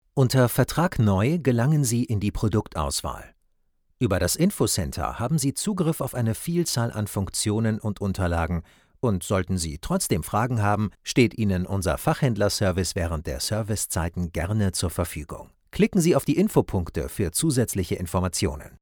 Bekannt aus Funk und Fernsehen und bestens geeignet fĂŒr alles, was sympathisch, glaubwĂŒrdig und natĂŒrlich klingen soll.
Kein Dialekt
Sprechprobe: eLearning (Muttersprache):
My pleasant and trustworthy voice is also used for corporate films, audio books, web clips, phone systems or e-learning.